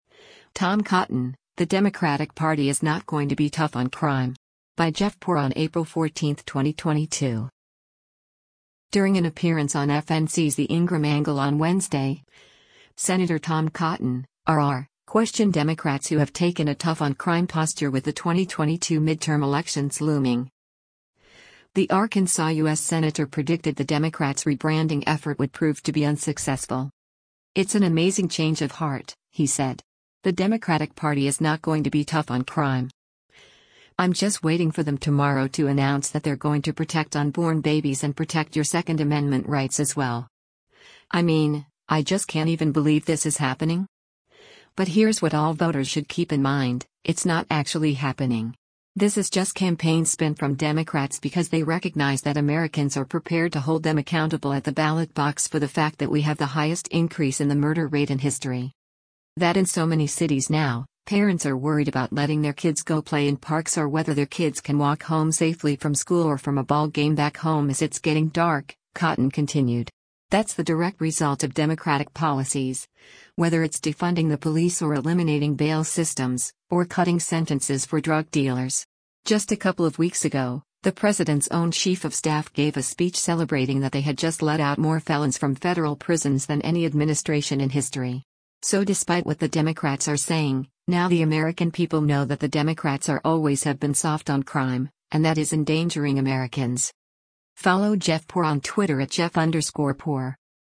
During an appearance on FNC’s “The Ingraham Angle” on Wednesday, Sen. Tom Cotton (R-AR) questioned Democrats who have taken a “tough on crime” posture with the 2022 midterm elections looming.